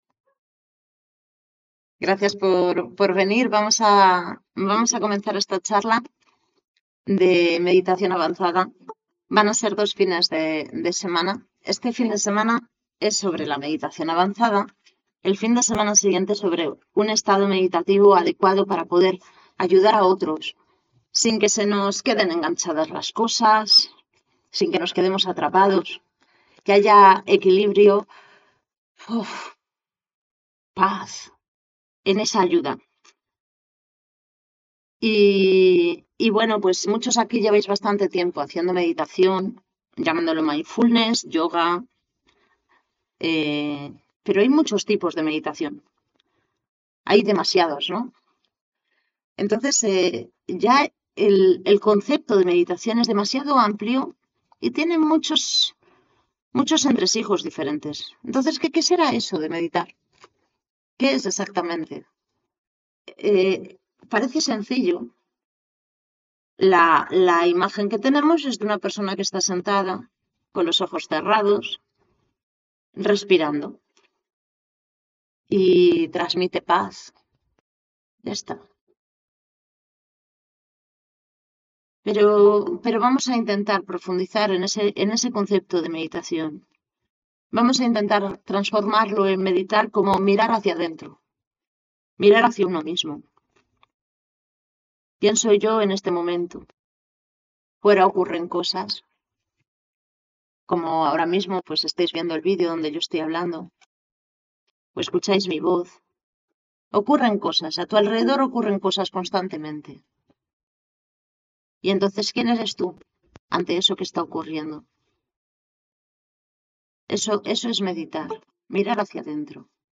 Charla previa formación de Meditación